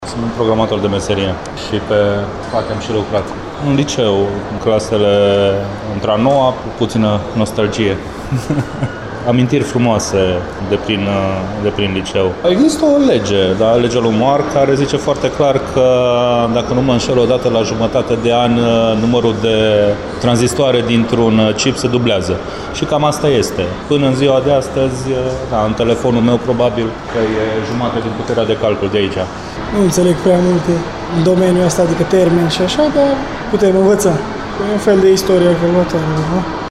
Timişorenii care au trecut pragul expoziţiei, nu au făcut doar o incursiune în epoca primelor calculatoare ci au şi retrăit perioada când învăţau despre ele:
insert2-voxuri.mp3